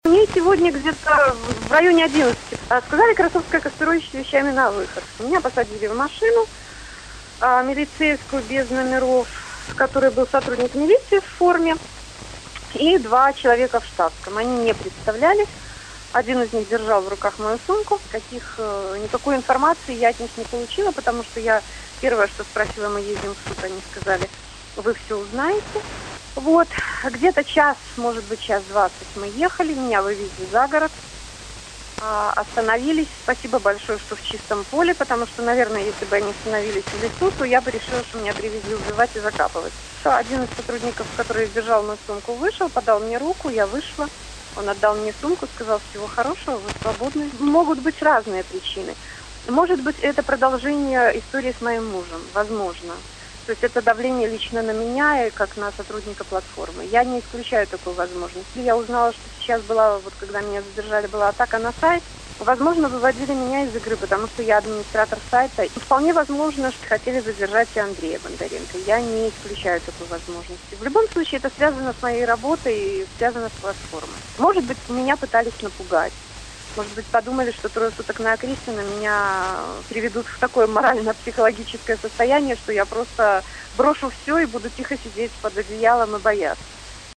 першае інтэрвію на волі